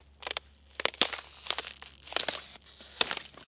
扑克筹码 " 扑克筹码2
描述：单个扑克牌筹码的叮当声。 这种声音来自于粘土/陶瓷扑克筹码，而不是廉价的塑料筹码。
Tag: 堆栈 芯片 声音 粘土 效果 FX 芯片 clinks 扑克 声音